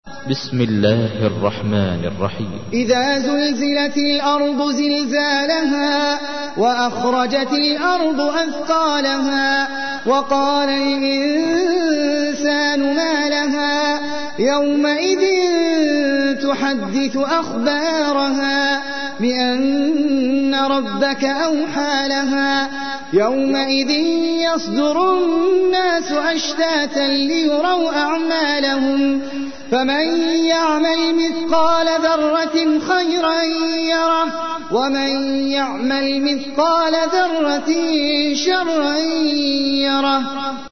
تحميل : 99. سورة الزلزلة / القارئ احمد العجمي / القرآن الكريم / موقع يا حسين